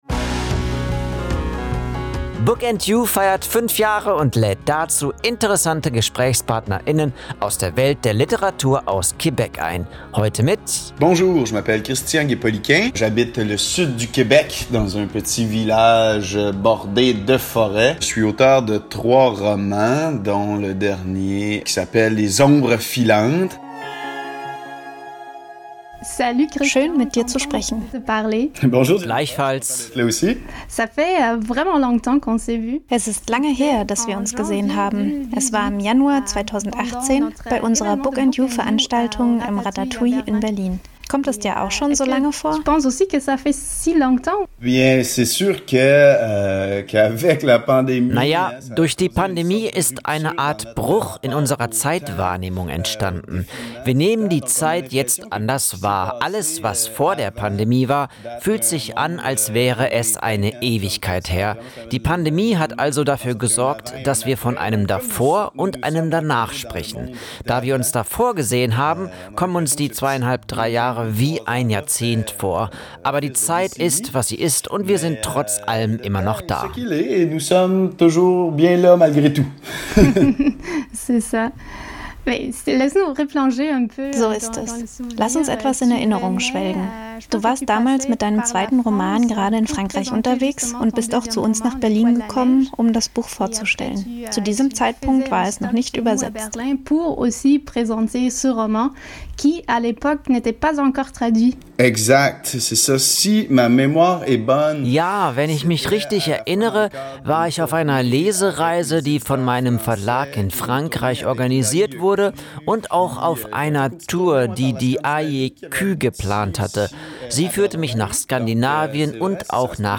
Deutsche Stimmen